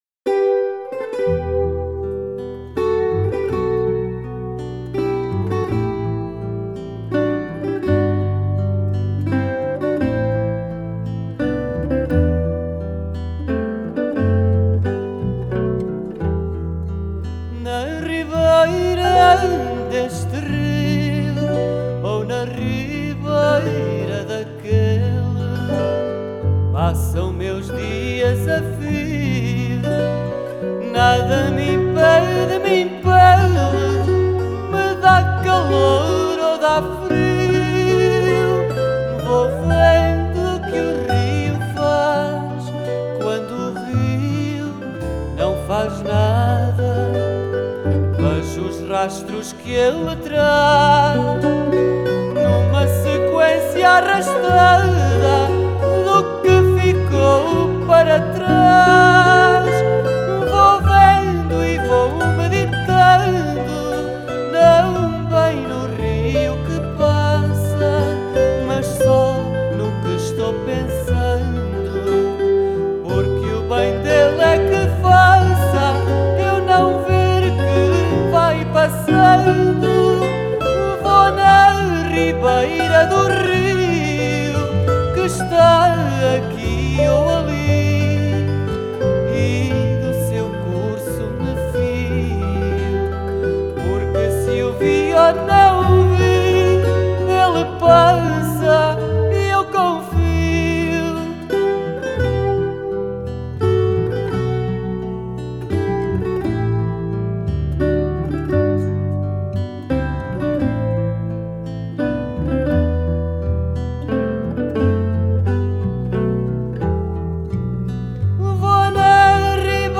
Genre: Folk, World, Fado